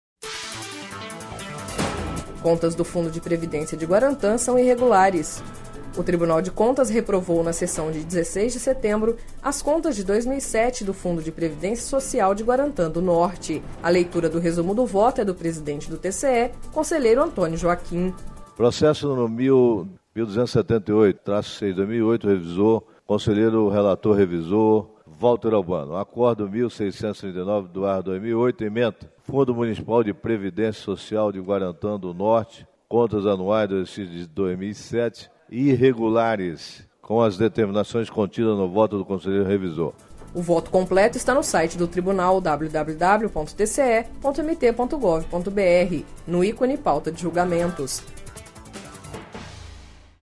A leitura do resumo do voto é do presidente do TCE-MT, Antonio Joaquim.